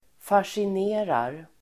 Uttal: [fasjin'e:rar]